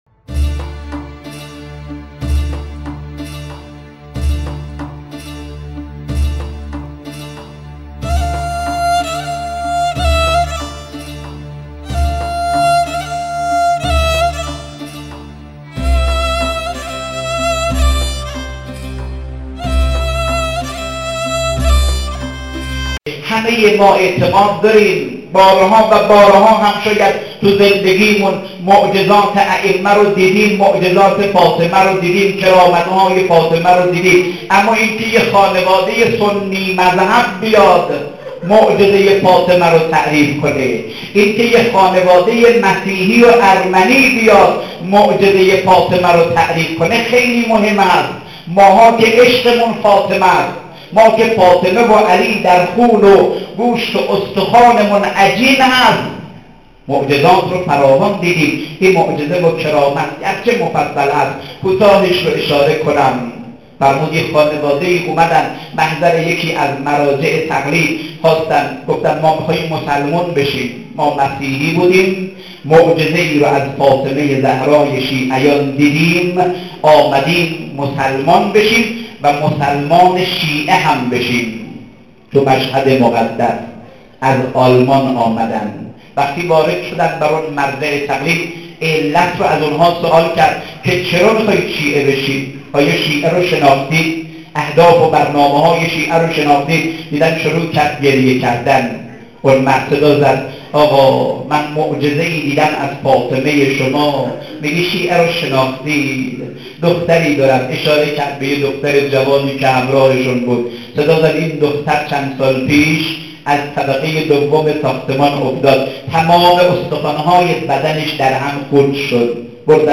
شفای ارمنی توسط حضرت زهرا, سخنرانی